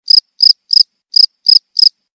Genre: Nada dering binatang